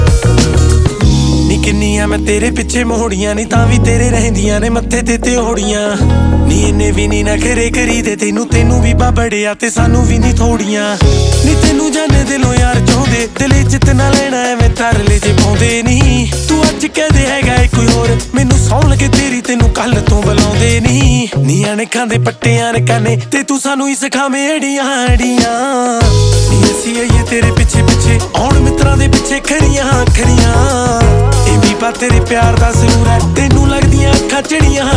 Pop Hip-Hop Rap
Жанр: Хип-Хоп / Рэп / Поп музыка